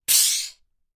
自行车打气筒 " 自行车打气筒塑料缓释15
描述：使用Zoom H6和Beyerdynamic MC740录制的自行车泵。
标签： 天然气 阀门 压力
声道立体声